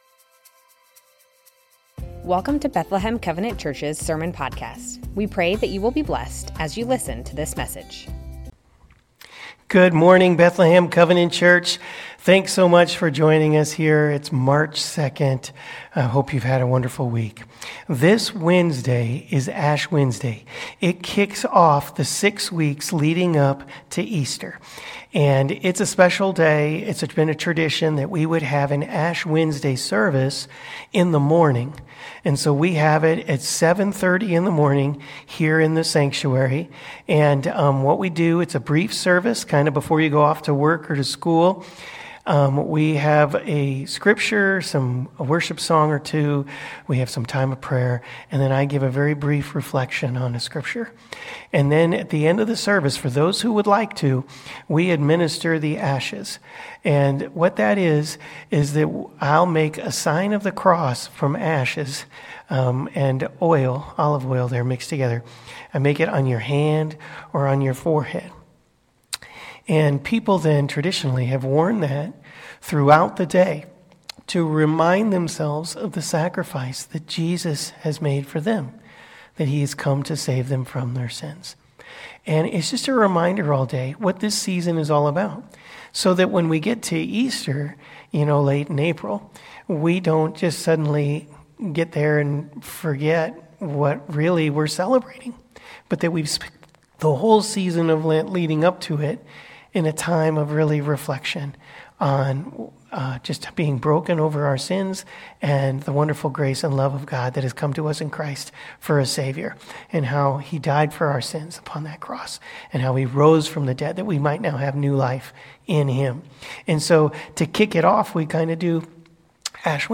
Bethlehem Covenant Church Sermons Matthew 6:5-18 - Prayer Mar 02 2025 | 00:42:01 1x 00:00 / 00:42:01 Subscribe Share Spotify RSS Feed Share Link Embed